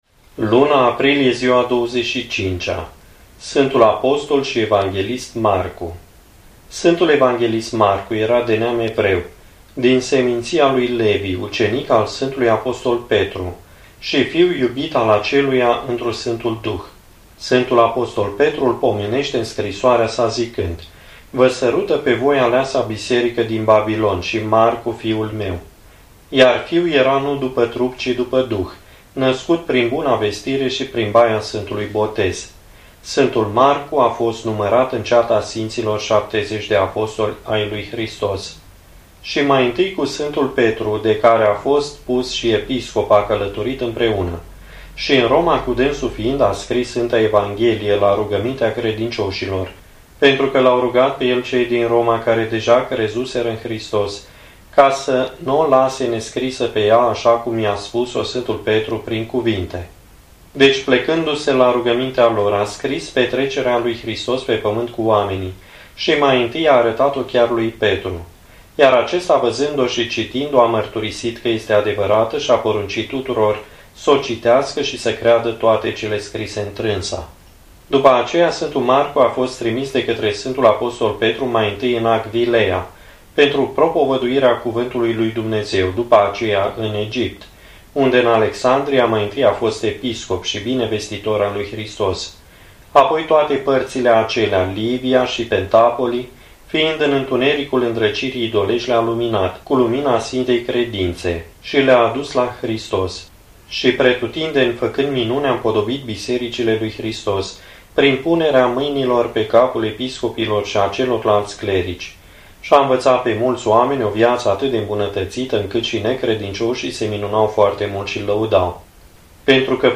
Index of /carti audio/vietile sfintilor/04.Vietile sfintilor pe Aprilie/25.